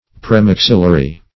Search Result for " premaxillary" : The Collaborative International Dictionary of English v.0.48: Premaxillary \Pre*max"il*la*ry\, a. (Anat.)